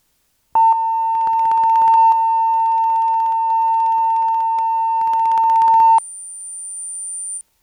動作音
【Auto-BLE信号音】